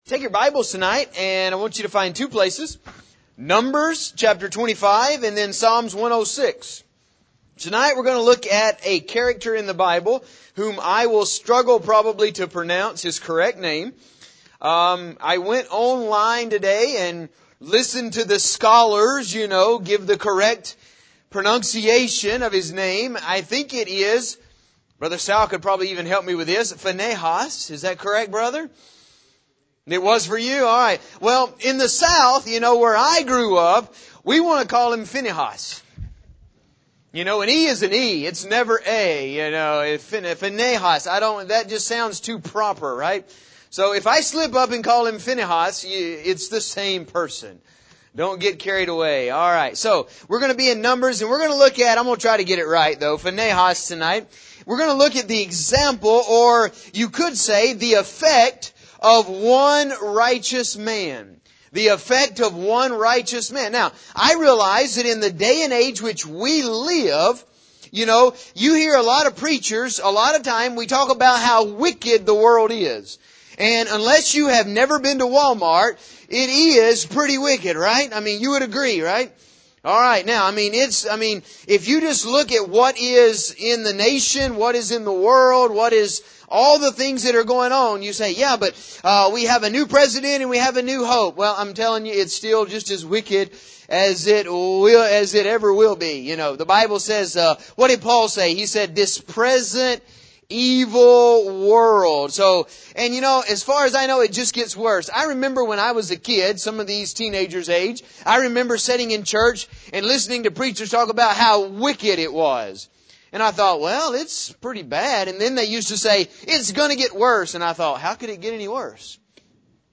There are many instances in the Bible of one person making a difference for example; David, Ruth, Elijah, Moses etc. In this sermon we will look at Phinehas and how this one man made a difference during a plague that God had put on Israel.